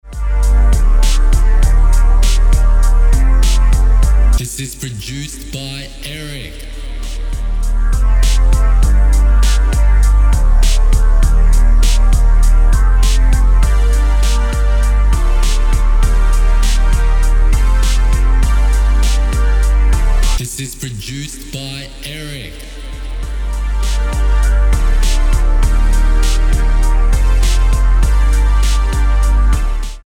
Key: G minor Tempo: 100BPM Time: 4/4 Length: 3:12